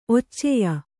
♪ occeya